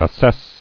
[as·sess]
As*sess"a*ble , a. Liable to be assessed or taxed; as, assessable property.